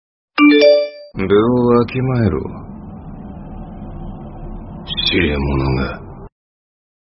Som de alarme do Sukuna Toque de notificação da voz de Sukuna
Categoria: Toques